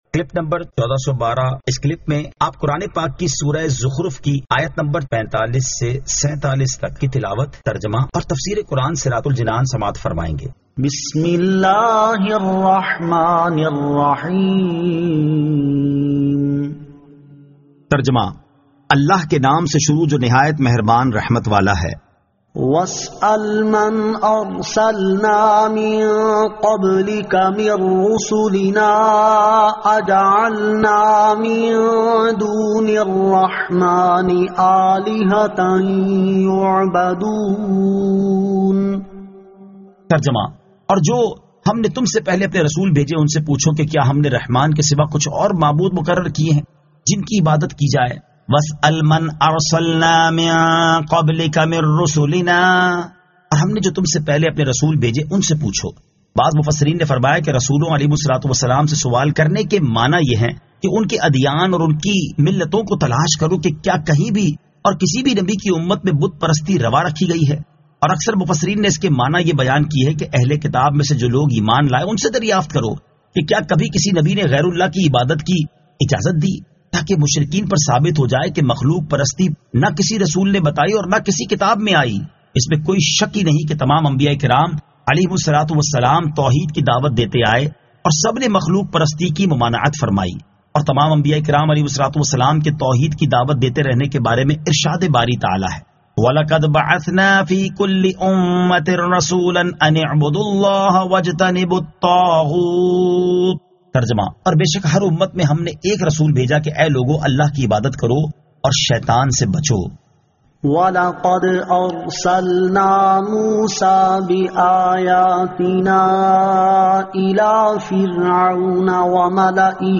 Surah Az-Zukhruf 45 To 47 Tilawat , Tarjama , Tafseer